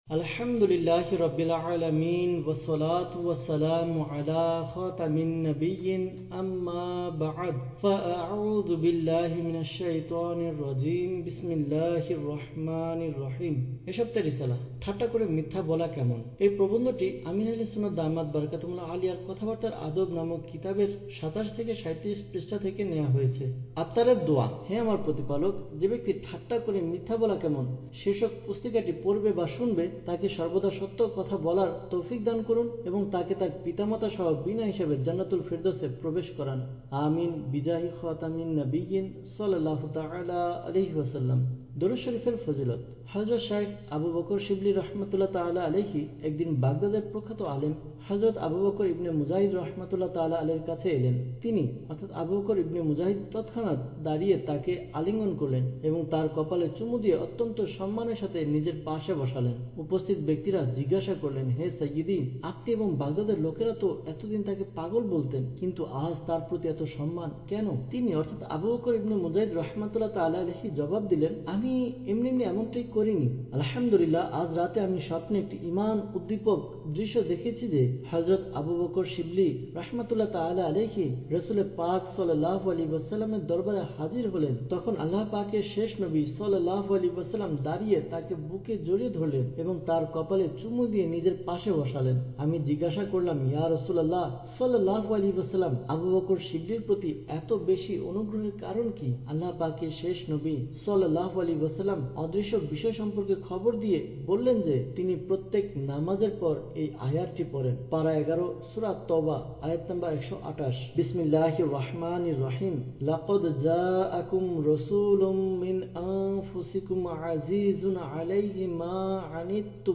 Audiobook – ঠাট্টা করে মিথ্যা বলা কেমন?